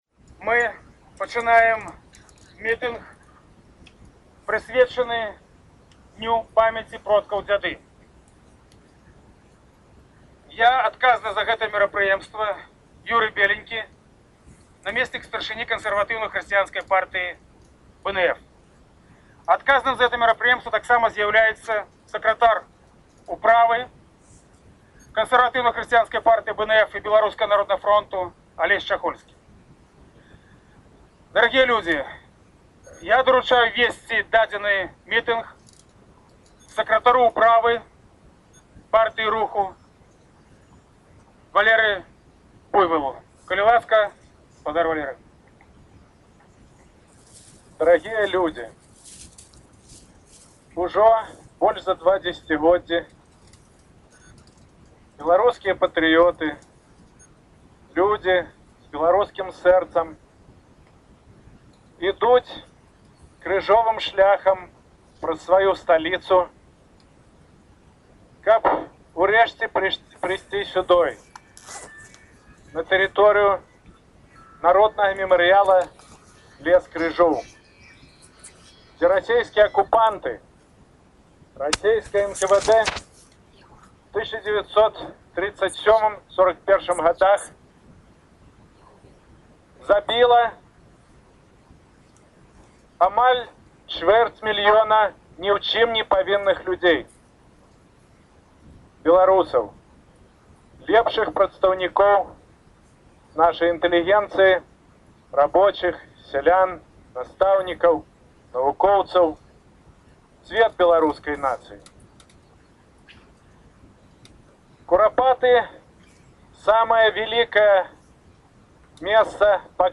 зачытвае зварот Зянона Пазьняка